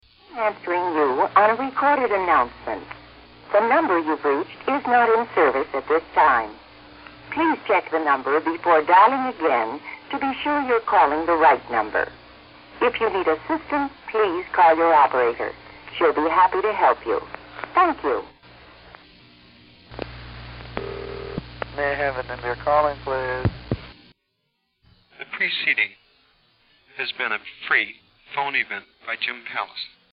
The Phoneyvent would be cued up on an audio cassette tape player.
This very first Phoneyvent was almost completely "transparent".